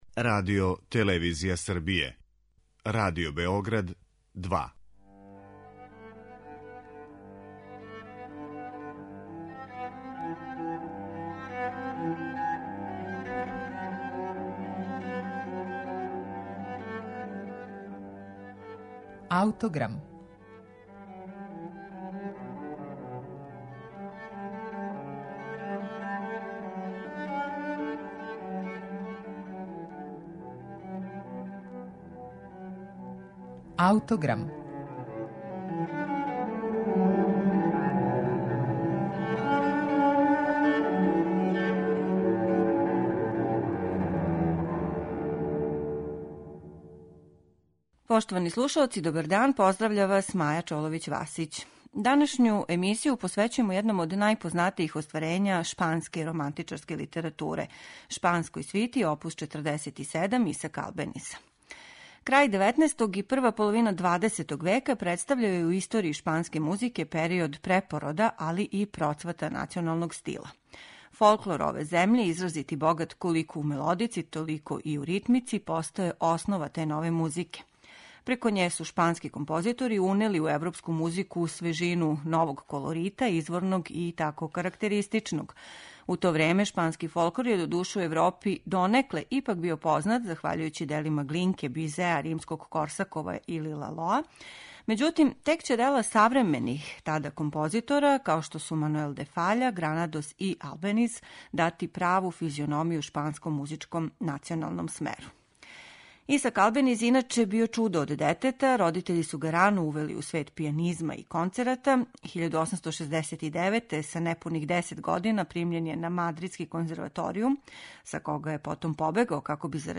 Ово дело данас ћете слушати у извођењу пијанисткиње Алисијe де Лароча.